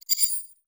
Simple Digital Connection 11.wav